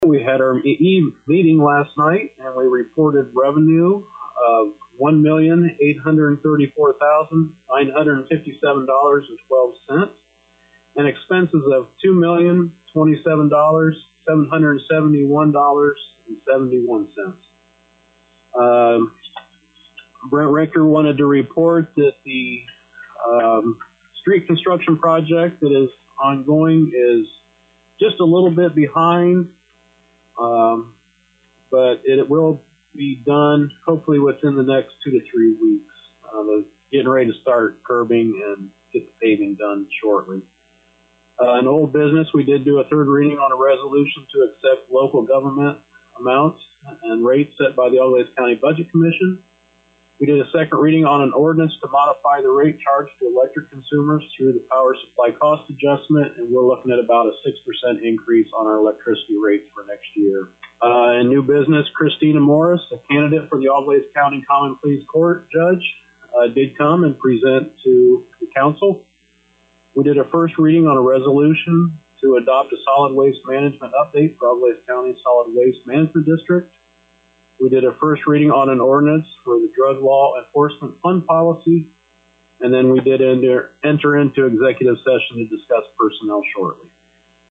For a summary with New Bremen Mayor Bob Parker: